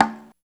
80 BONGO 2.wav